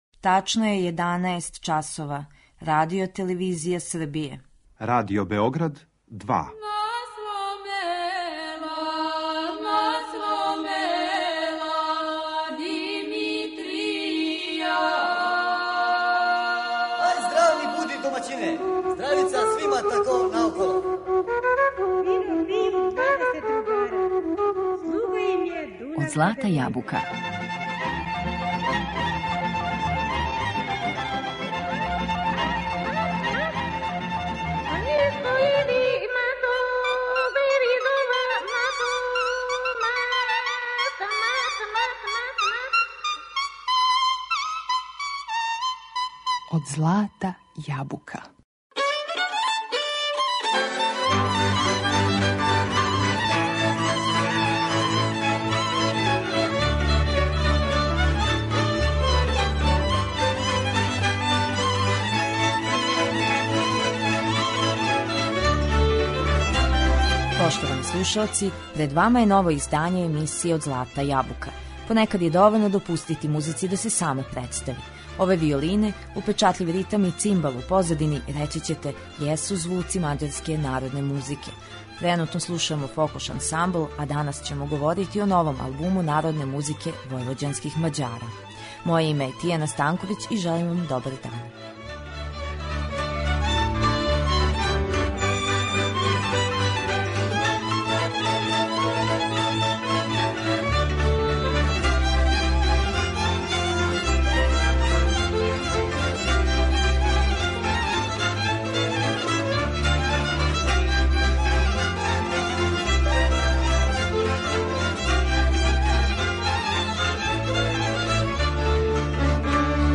На албуму се налази четрнаест приповедања, народних песама и инструменталних мелодија војвођанских Мађара, компактно уклопљених у нешто више од 43 минута.